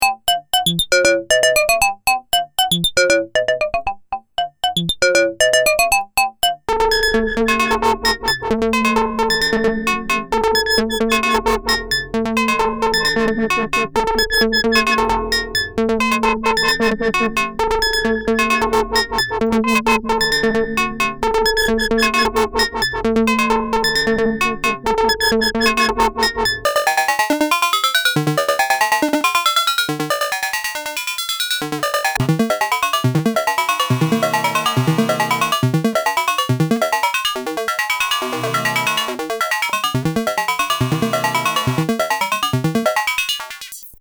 Workshop für Metal-Pling aus Synthesizer-Magazin 12
(enthält in Reihenfolge der Erklärung Beispiele zum Workshoptext. Endet mit Öffnen der Releasephase als "Hallersatz" und beginnt mit den beiden Dreieckswellen mit Crossmodulation.)
Jupiter6Pling_workshop.mp3